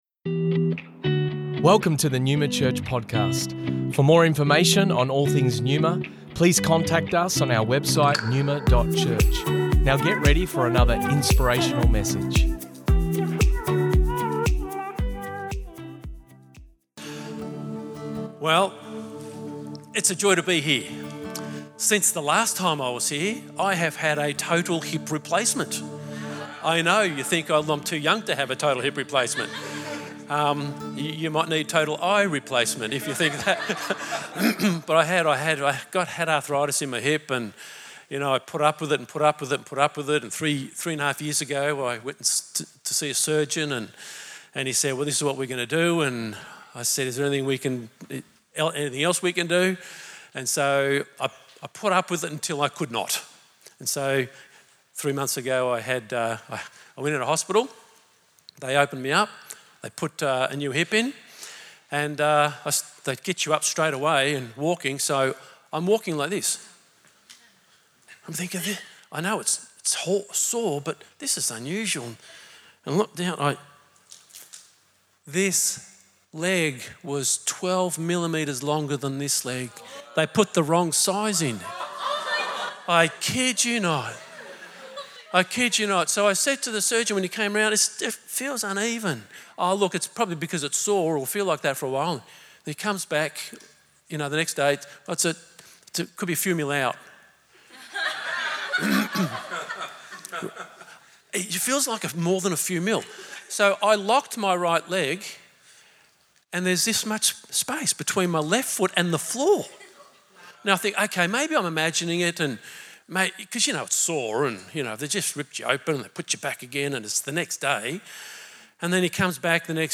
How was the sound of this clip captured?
Originally recorded at Neuma Melbourne West On the 30th of July 2023